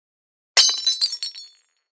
Звуки посуды
Блюдечко треснуло